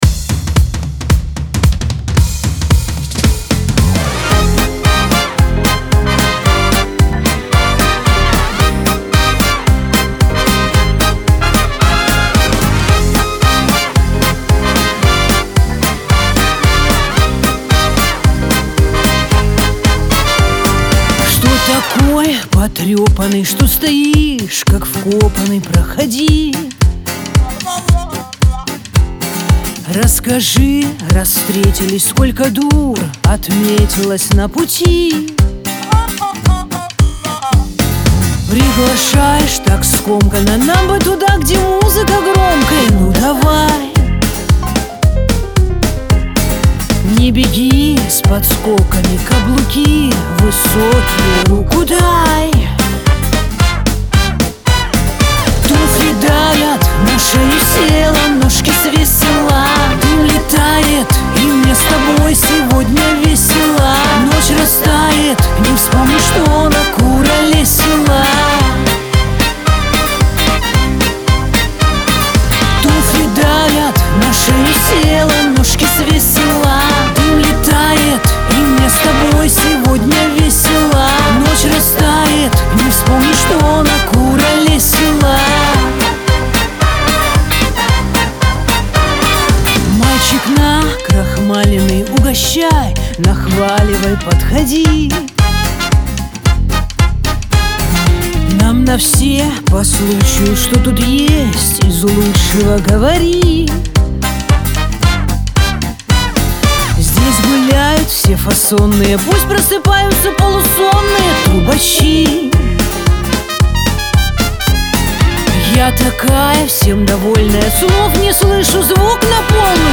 эстрада
pop , Веселая музыка , диско